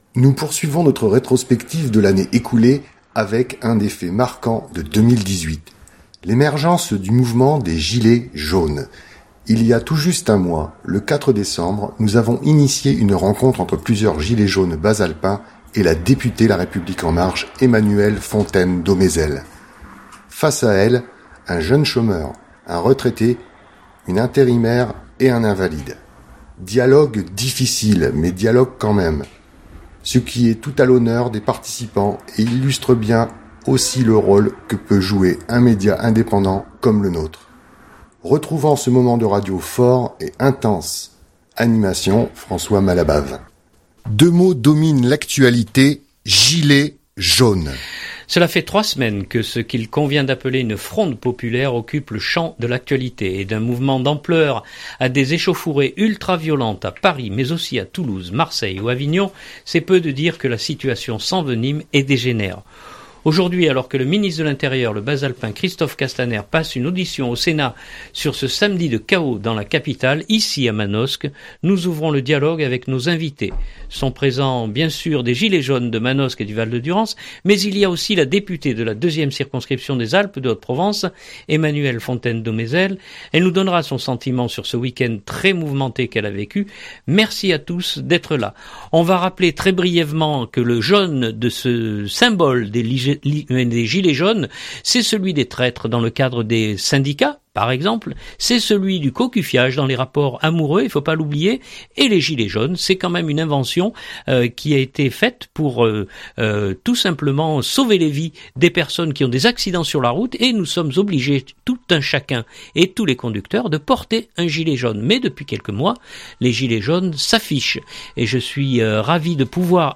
L'émergence du mouvement des Gilets Jaunes. Il y a tout juste un mois, le 4 décembre, nous avons initié une rencontre entre plusieurs gilets jaunes bas-alpins et la députée La République En Marche Emmanuelle Fontaine-Domeizel. Face à elle, un jeune chôme ur, un retraité, une intérimaire et un invalide.
Retrouvons ce moment de radio fort et intense.